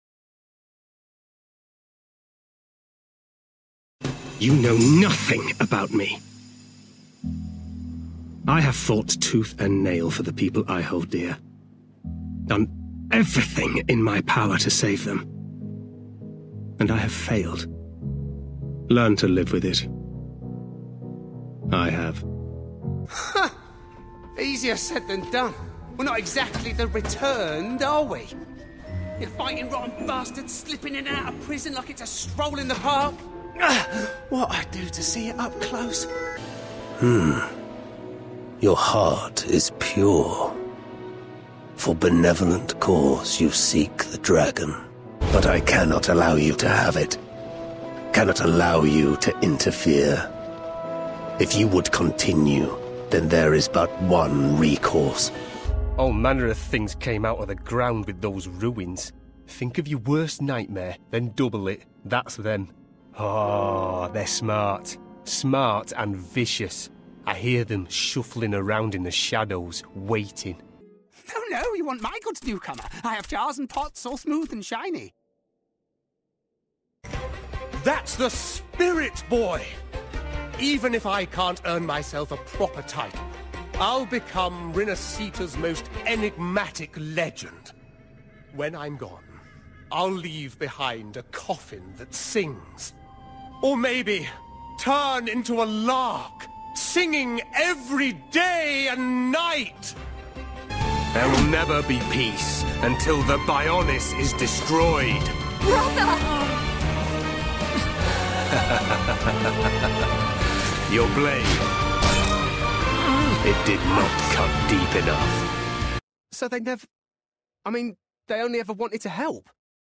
Gaming
Northern (English), Yorkshire, Lancashire, Standard English/RP, London/Cockney, American, Mancunian, Irish, Newcastle/Geordie
Actors/Actresses, Corporate/Informative, Natural/Fresh, Smooth/Soft-Sell, Character/Animation, Comedy, Upbeat/Energy, Mature/Sophisticated